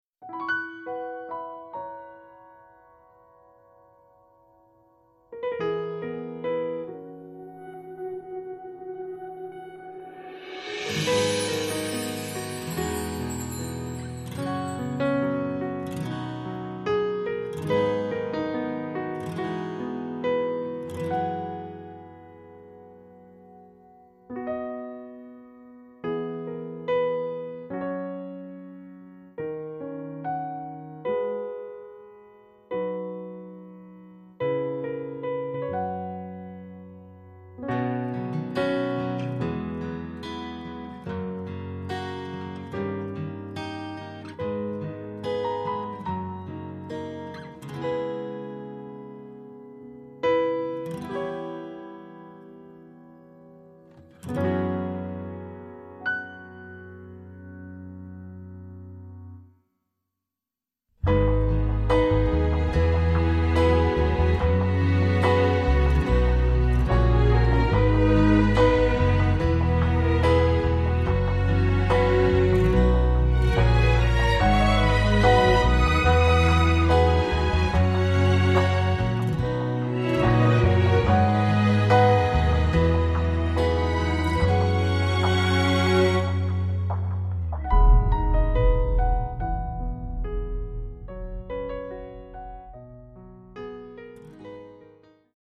歌曲调式：B大调